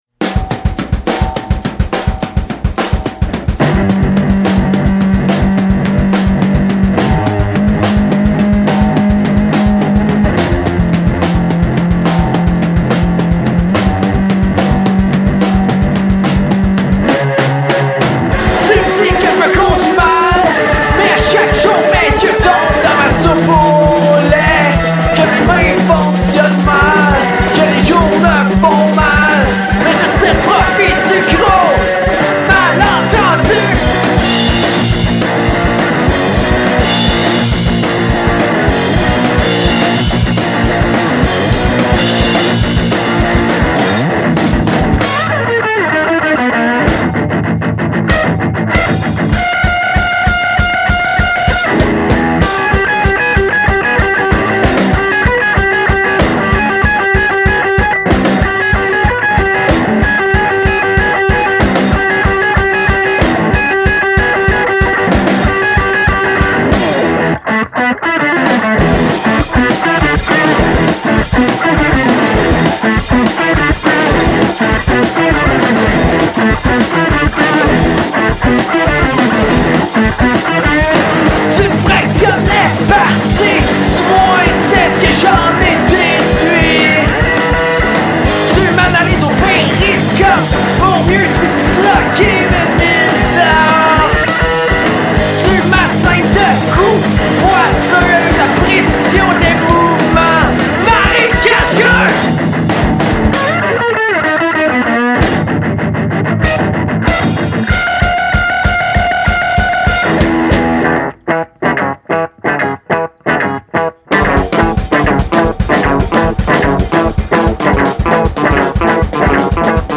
Genres: Noise rock, math rock